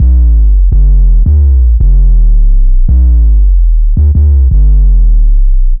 Index of /90_sSampleCDs/Zero-G - Total Drum Bass/Instruments - 1/track06 (Bassloops)